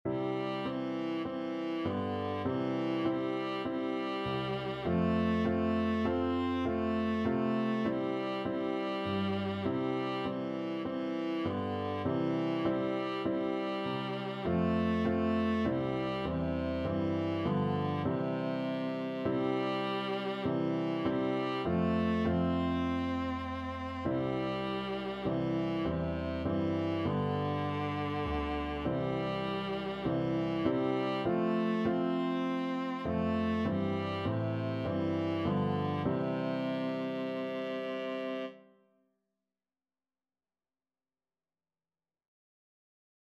4/4 (View more 4/4 Music)
Viola  (View more Easy Viola Music)
Classical (View more Classical Viola Music)